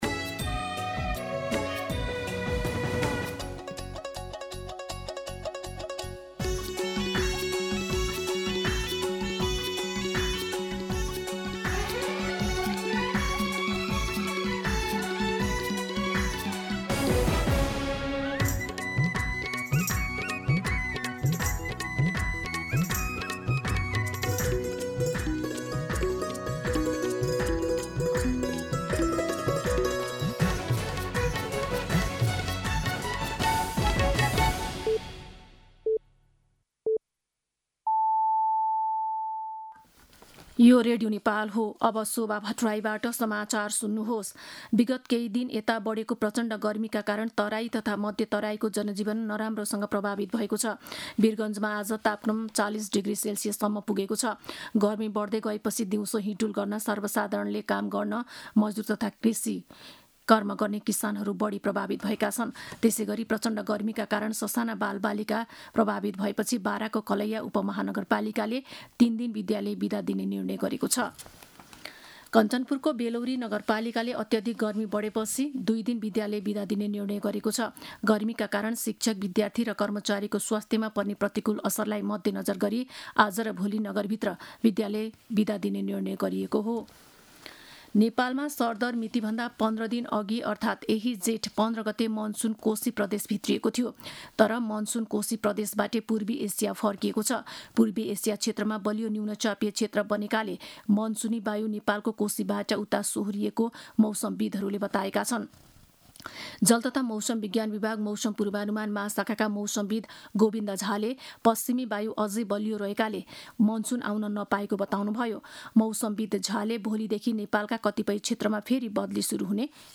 दिउँसो ४ बजेको नेपाली समाचार : २७ जेठ , २०८२
4pm-News-02-27.mp3